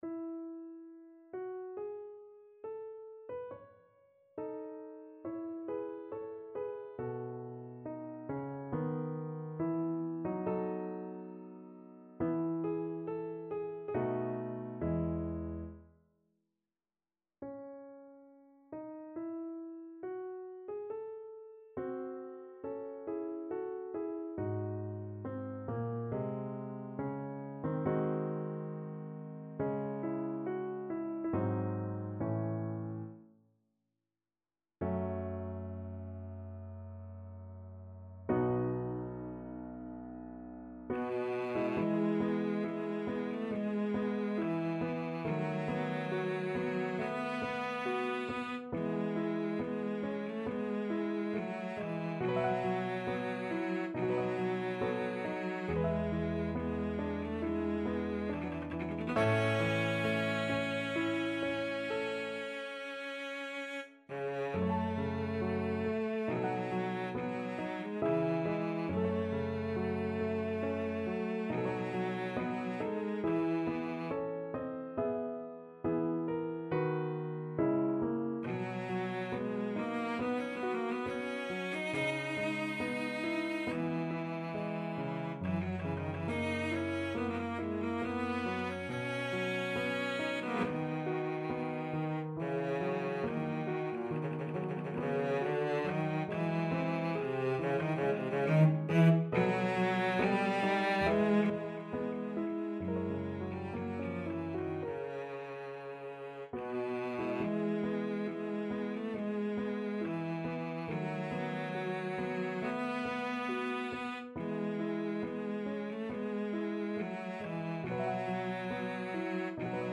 Larghetto (=80) =69
Bb3-Eb5
Classical (View more Classical Cello Music)